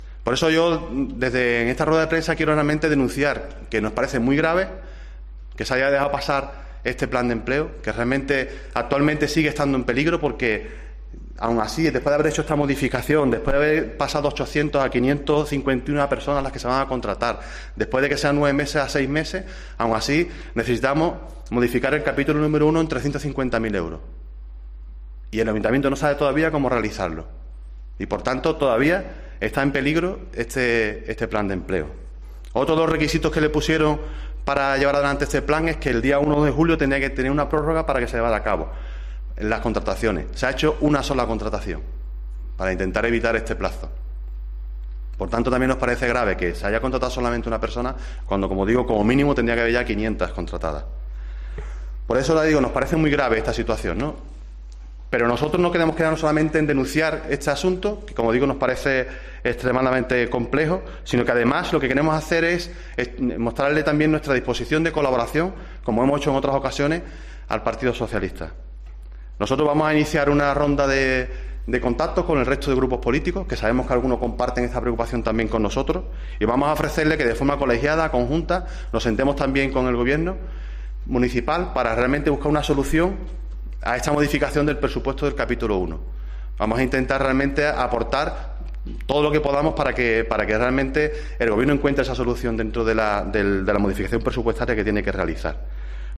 Ignacio Martínez, concejal popular, sobre el Plan de Empleo